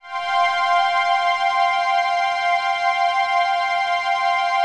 CHRDPAD013-LR.wav